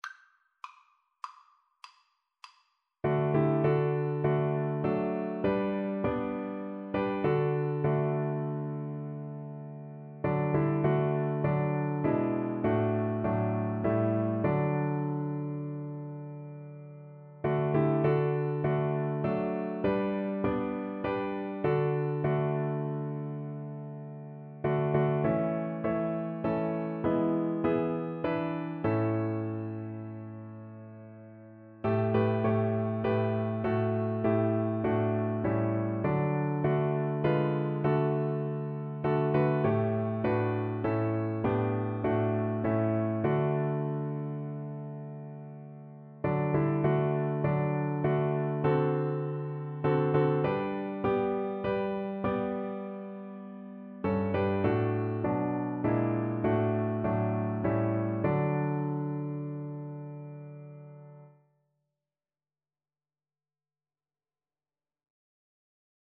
6/4 (View more 6/4 Music)
D major (Sounding Pitch) (View more D major Music for Cello )